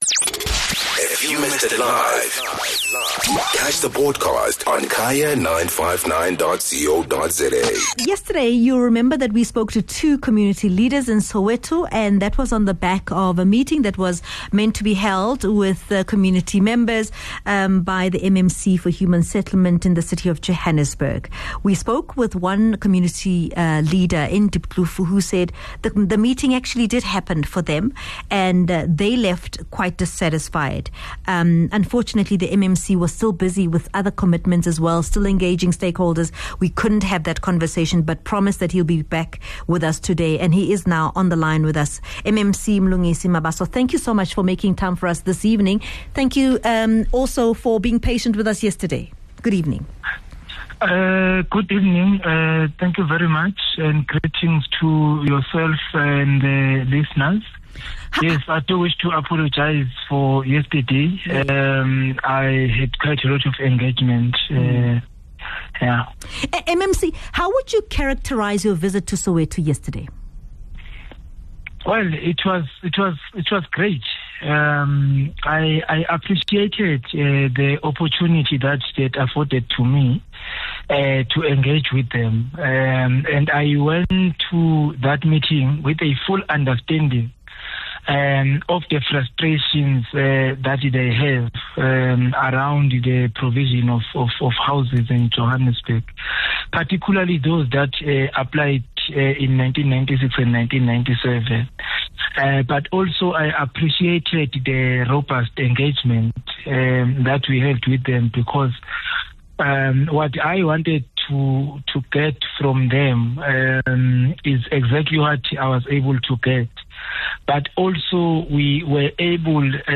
speaks to MMC Mabaso about his plans to assist residents of Soweto.